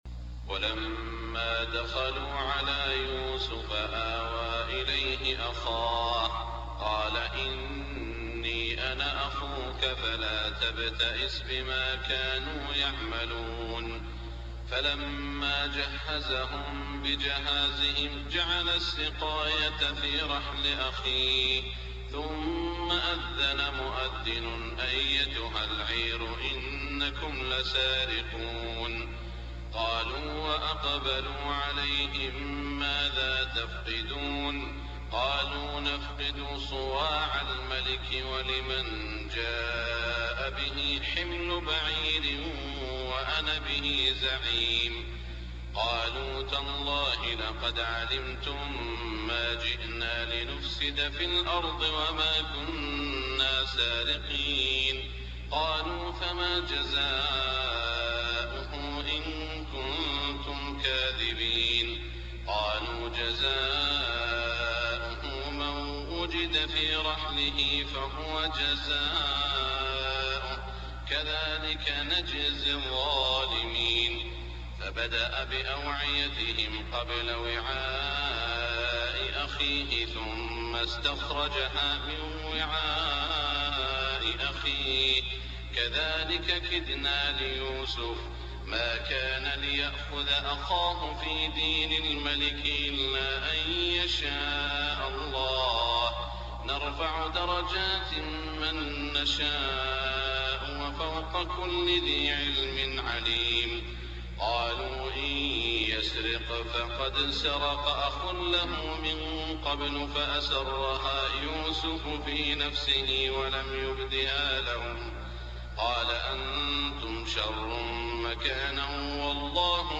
صلاة الفجر 3-4-1424هـ من سورة يوسف > 1424 🕋 > الفروض - تلاوات الحرمين